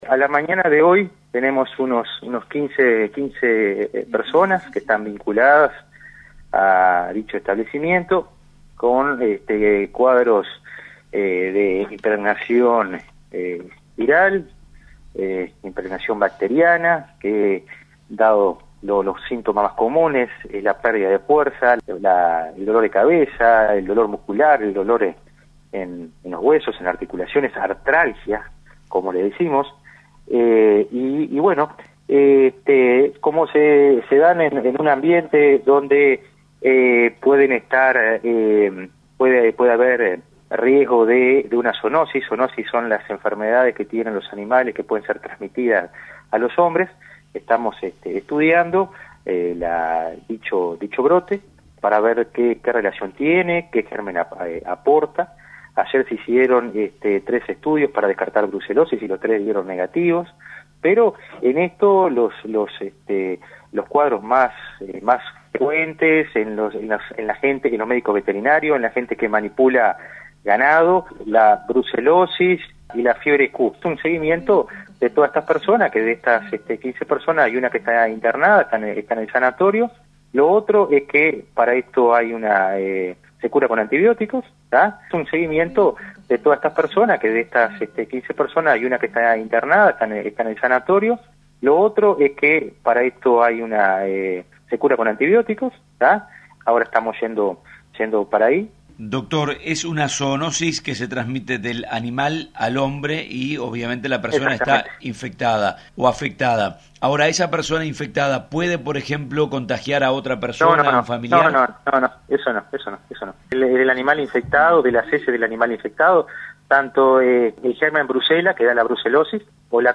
Escuchar al director departamental de salud de Tacuarembó, Juan Motta: